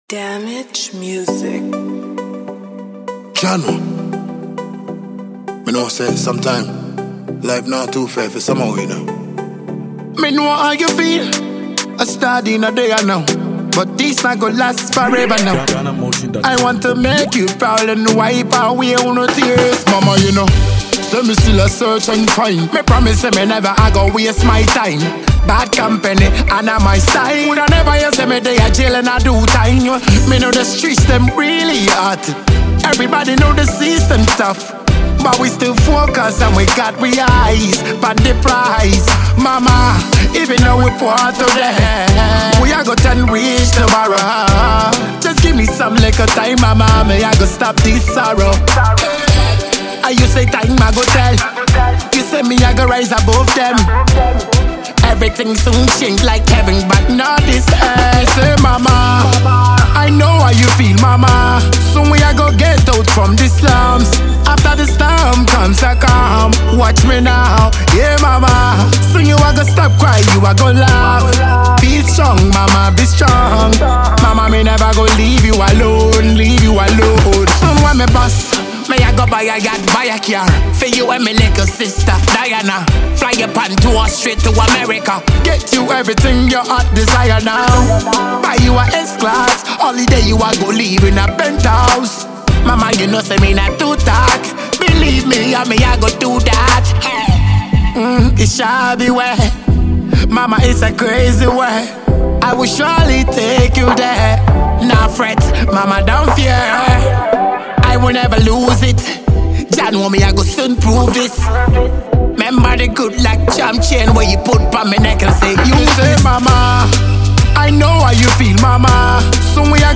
Genre: Reggae Dancehall